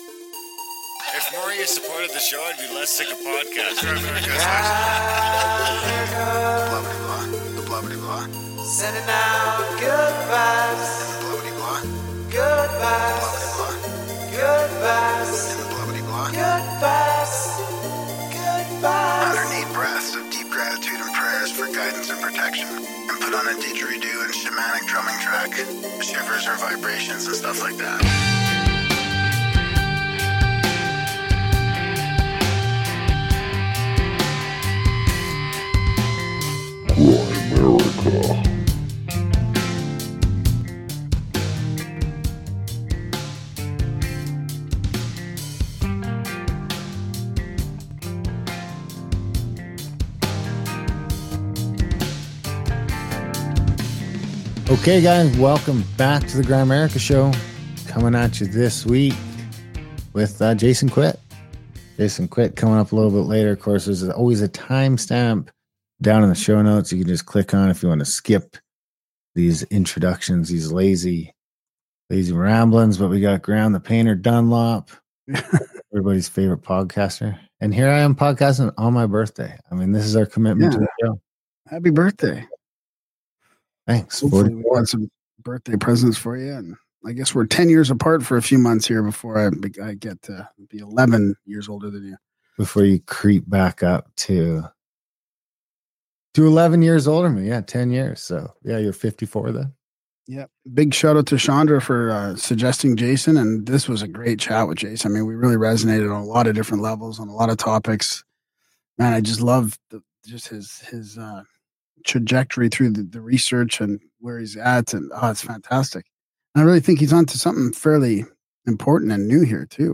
DownloadInterview starts at 25:15